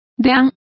Complete with pronunciation of the translation of deans.